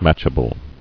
[match·a·ble]